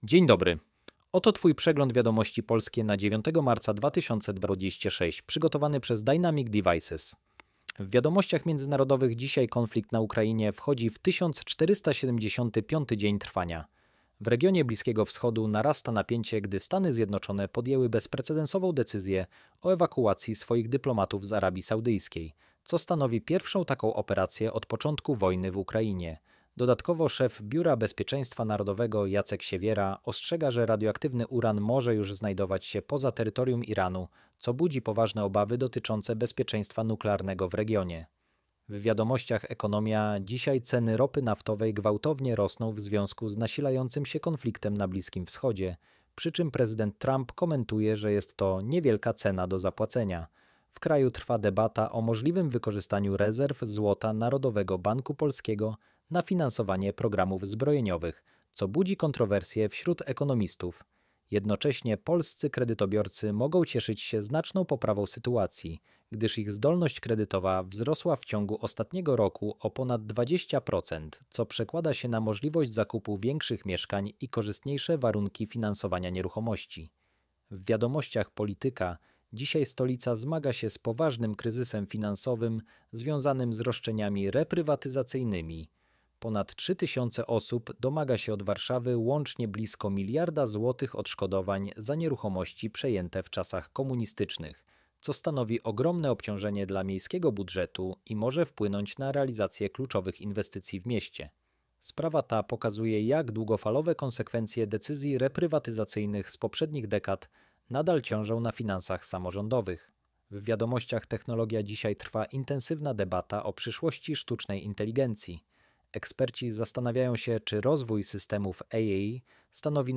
Codzienny przegląd wiadomości audio generowany przez AI dla 12 kwietnia 2026 prezentowany przez Dynamic Devices. Profesjonalny polski głos, zoptymalizowany dla czytników ekranu.